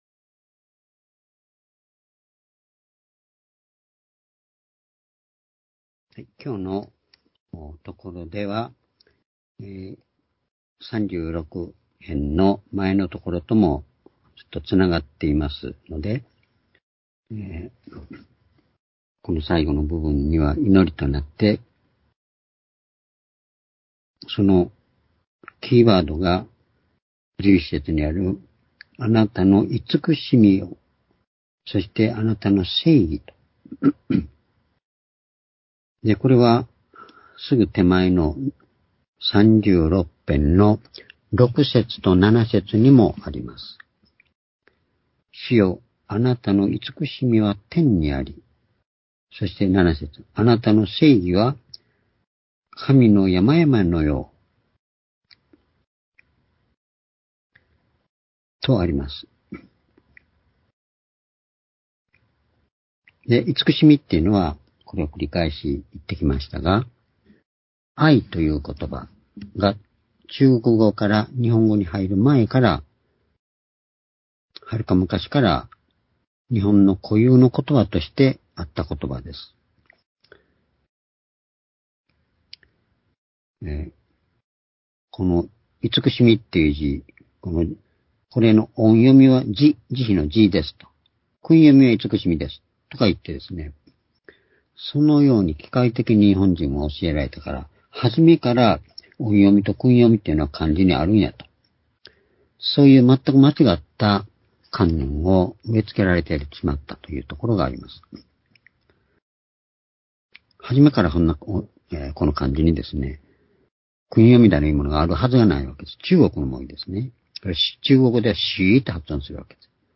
（主日・夕拝）礼拝日時 ２０２３年8月1日（夕拝） 聖書講話箇所 「神の慈しみと正義」 詩編36の11-13 ※視聴できない場合は をクリックしてください。